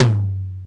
Index of /90_sSampleCDs/Classic_Chicago_House/Drum kits/kit05
cch_11_perc_tom_low_tubby.wav